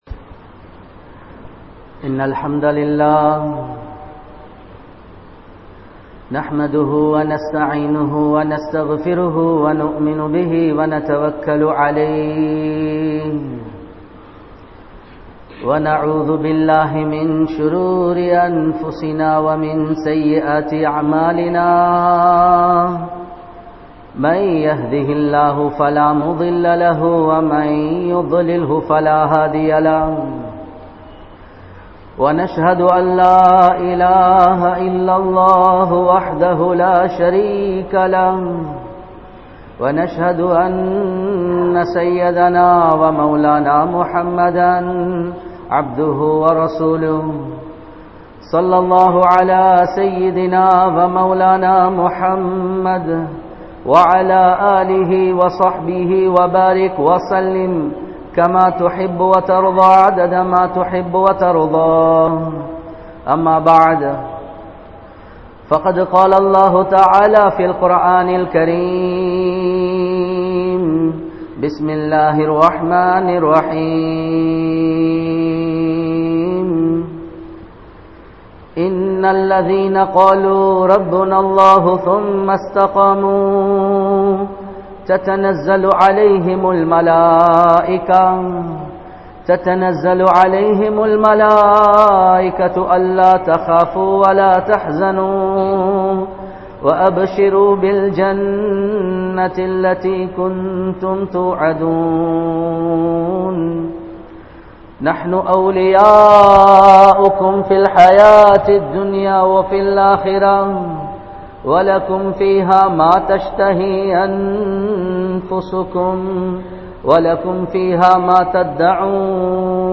Neengal PariPoorana Muslima? (நீங்கள் பரிபூரன முஸ்லிமா?) | Audio Bayans | All Ceylon Muslim Youth Community | Addalaichenai